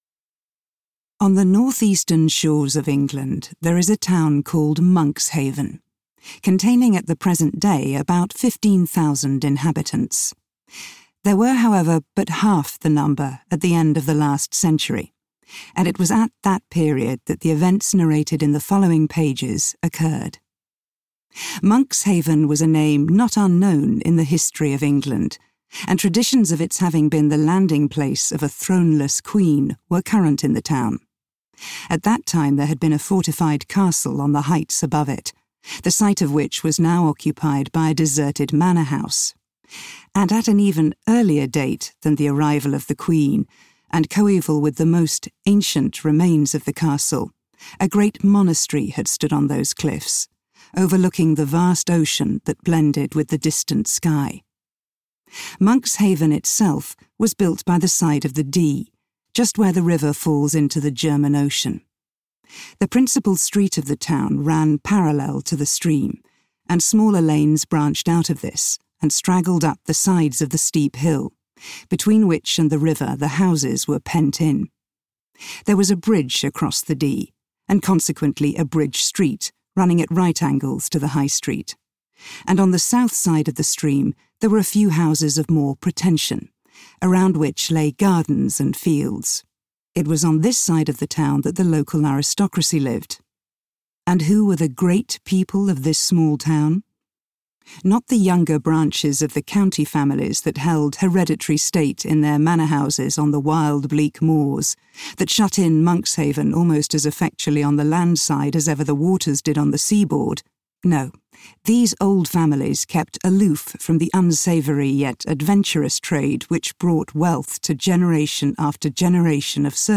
Sylvia’s Lovers audiokniha
Audiobook Sylvia’s Lovers, written by Elizabeth Gaskell.
Ukázka z knihy